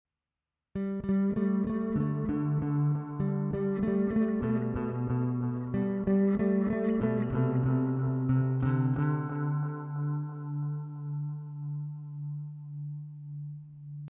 Beim lauteren hören mit Kopfhörern stelle ich doch ein iges an Nebengeräuschen fest, liegt vermutlich an meinen Lötkünsten.
Delay mit einem regelbaren LFO. Gut wie ich finde für Ambient.